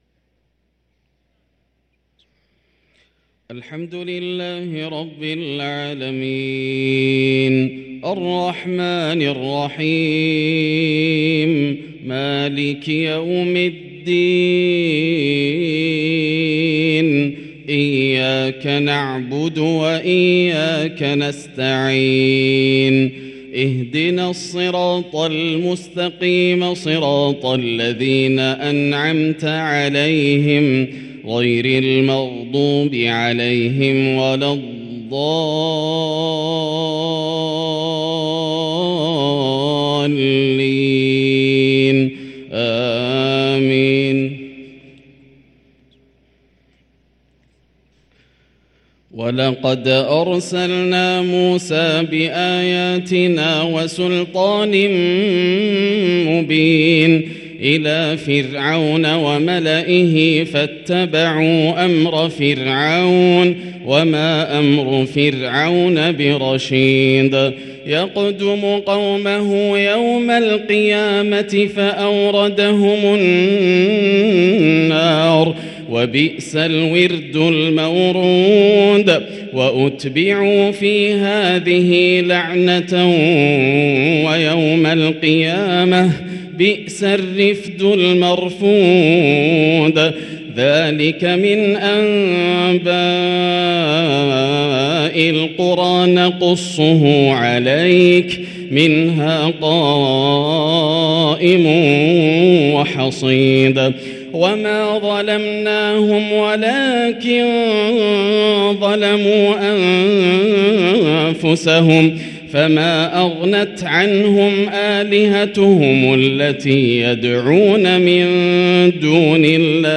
صلاة العشاء للقارئ ياسر الدوسري 8 شعبان 1444 هـ
تِلَاوَات الْحَرَمَيْن .